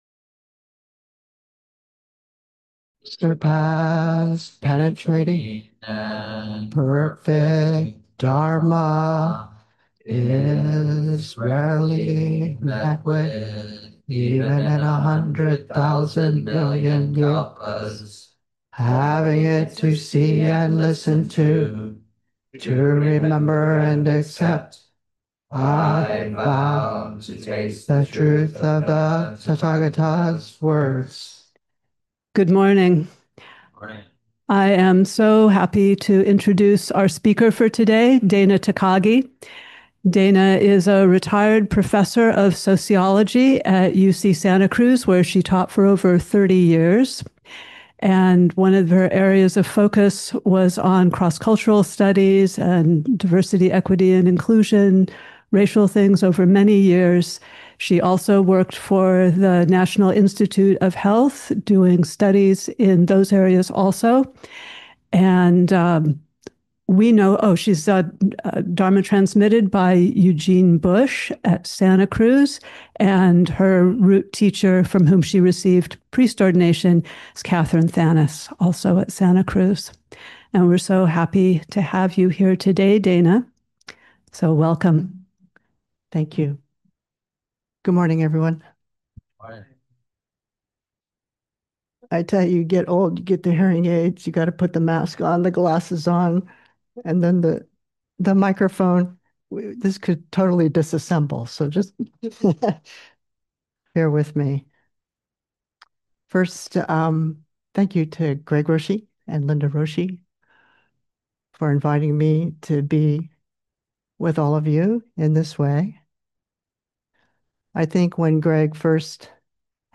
Lectures, talks, and classes on Zen Buddhism from Shogakuji Temple, Berkeley Zen Center, in Berkeley, California, USA.